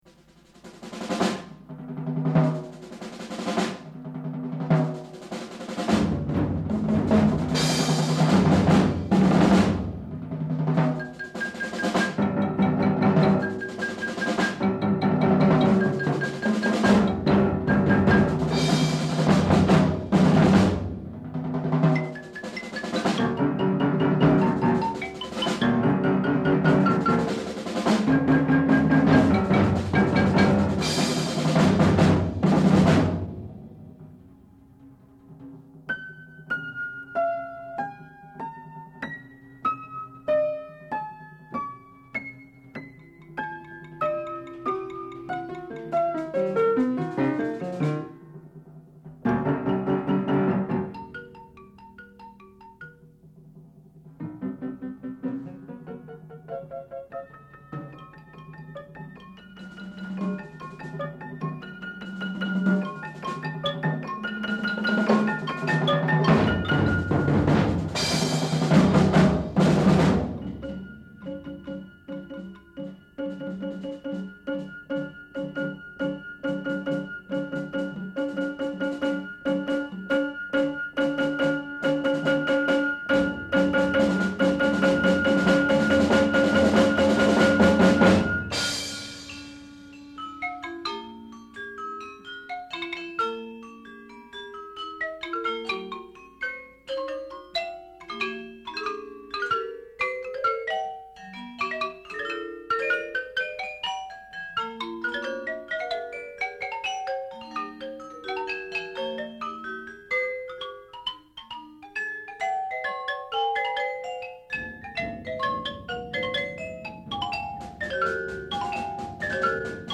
[1] Allegro view